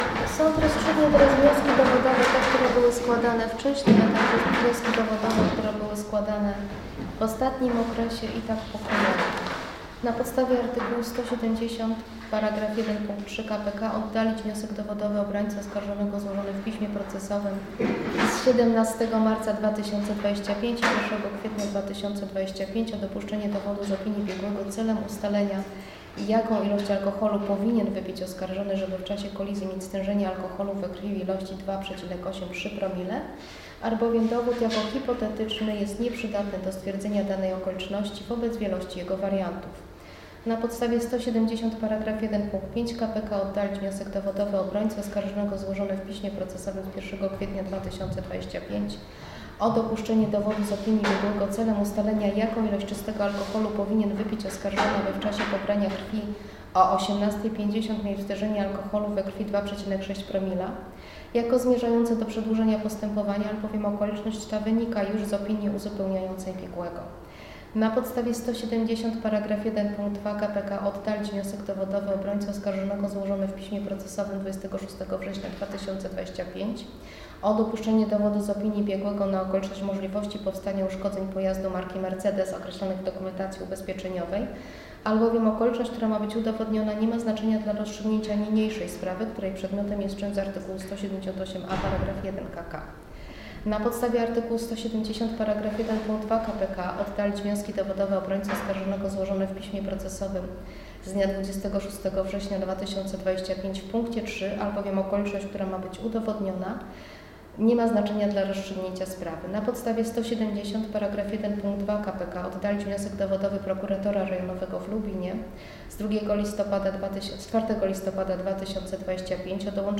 – krytycznie uznał burmistrz Chocianowa w wywiadzie.